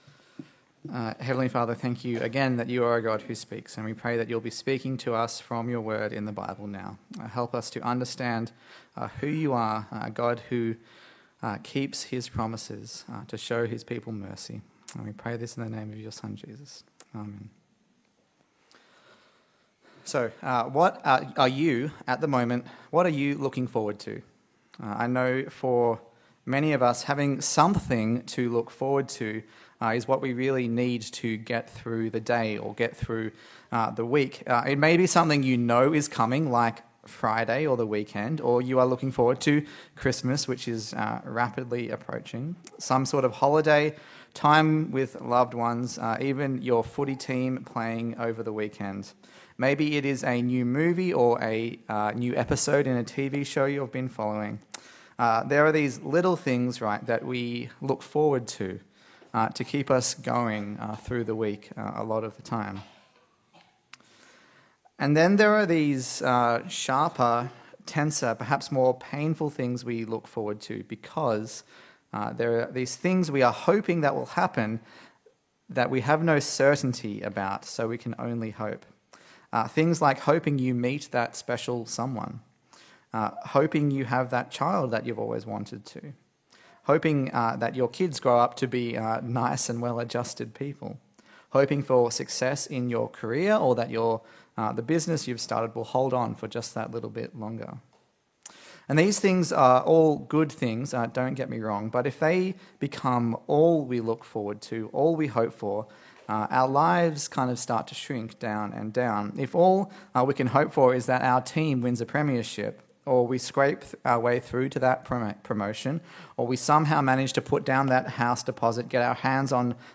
Service Type: Sunday Service A sermon in the series on the book of Luke